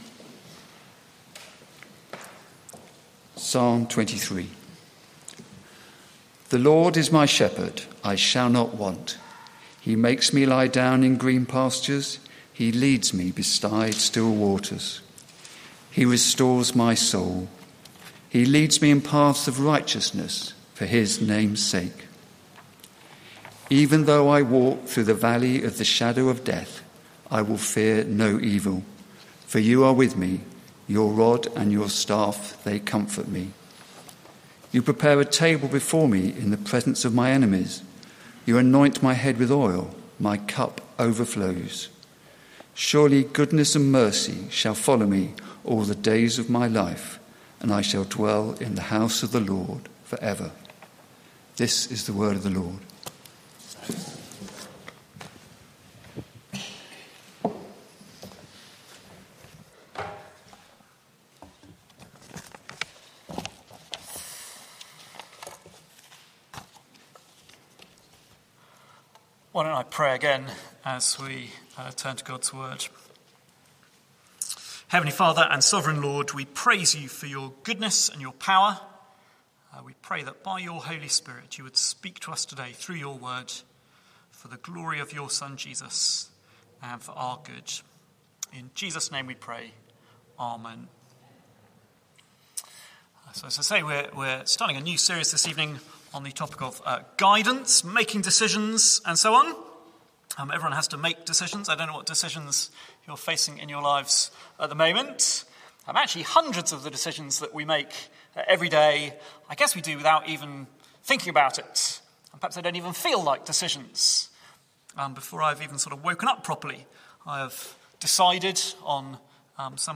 Media for Evening Meeting on Sun 05th Jan 2025 18:00